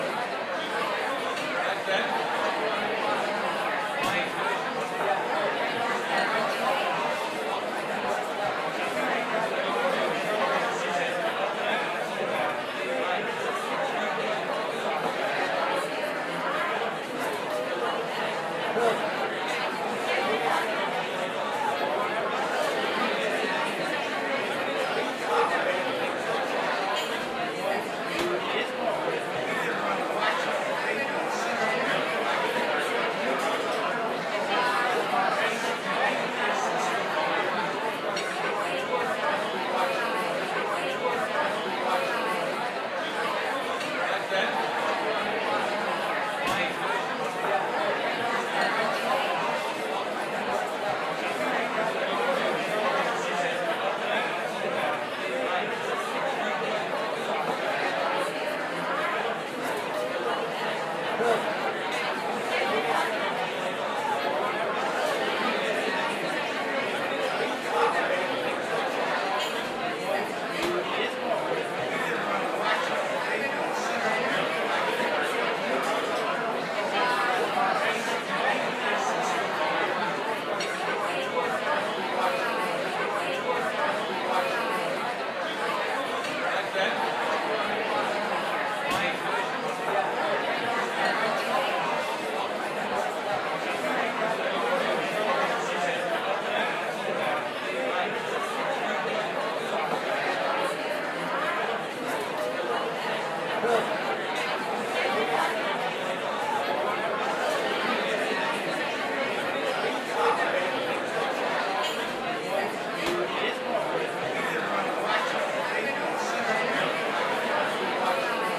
add new cafeteria ambience
teenageCafeteriaLoop.ogg